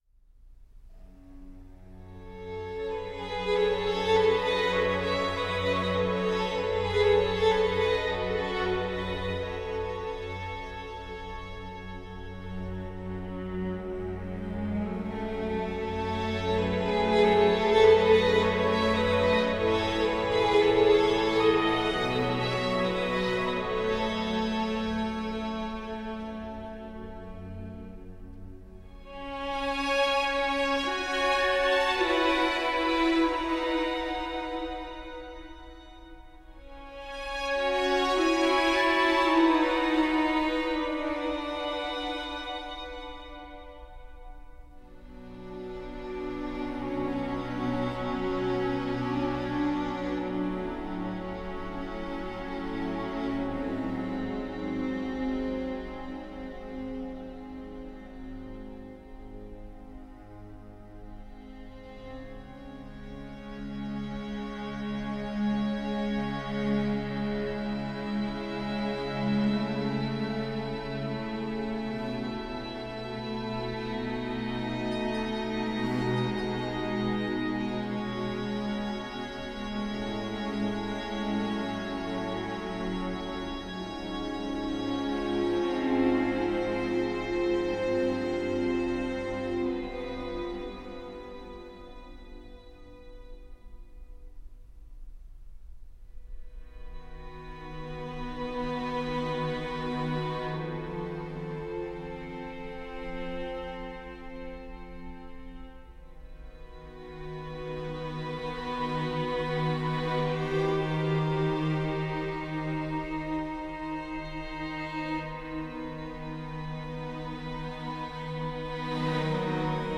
以下试听除弦乐外的其他乐器和打击乐均来自柏林系列，本站均可下载